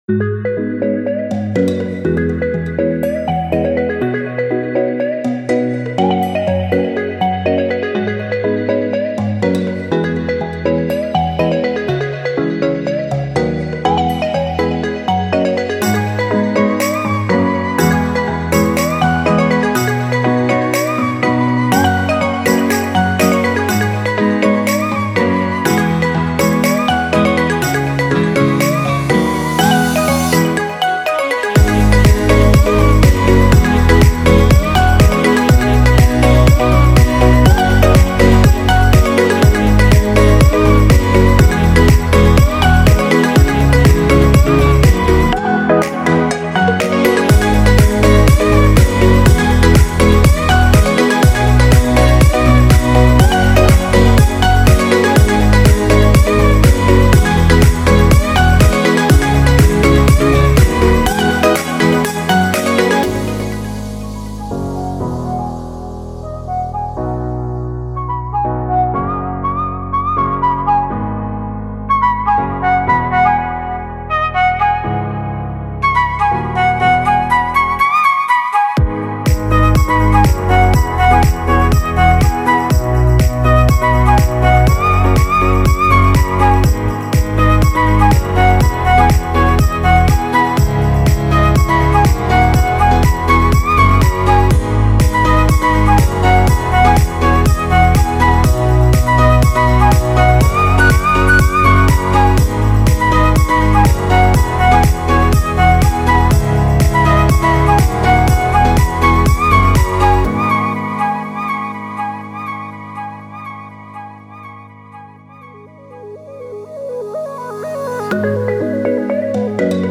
bg-music.b32229ea.mp3